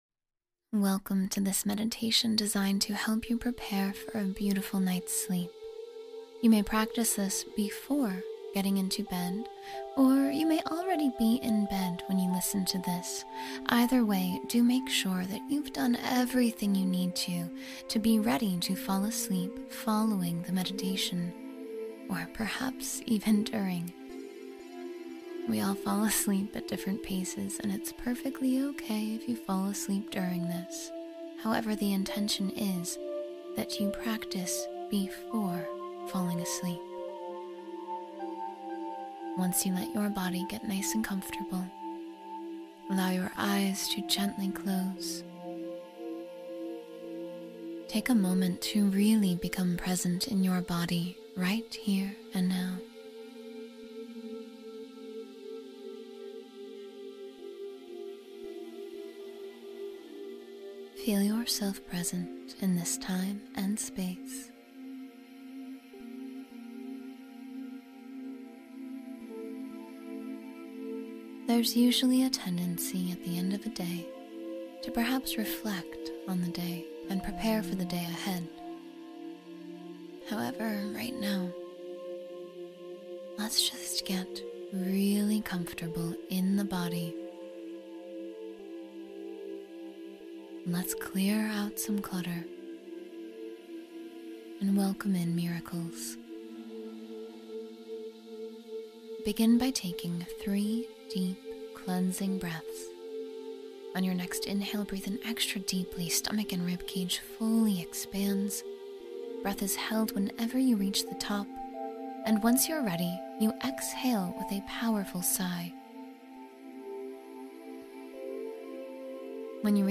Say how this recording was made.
That’s why all advertisements are placed at the beginning of each episode—so once you press play on Zen Meditation, nothing interrupts the space you’re creating for yourself, and so you can also support the continued growth of Zen Meditation.